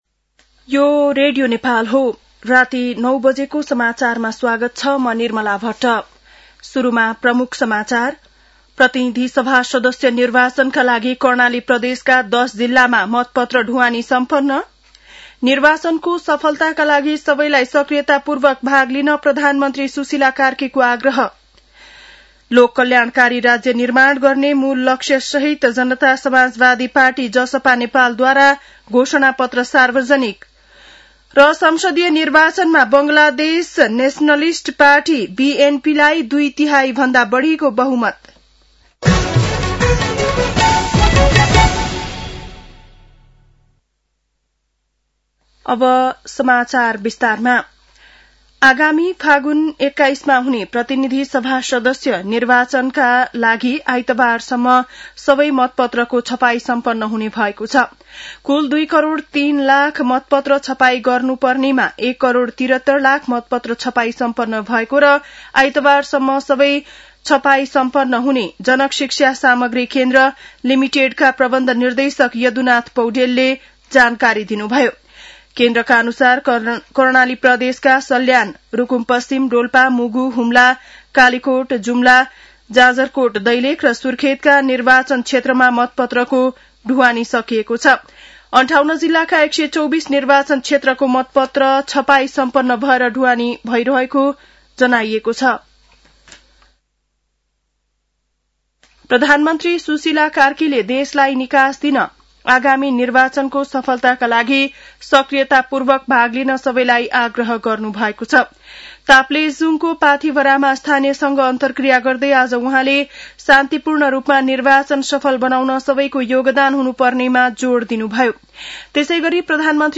बेलुकी ९ बजेको नेपाली समाचार : १ फागुन , २०८२
9-pm-nepali-news-1-5.mp3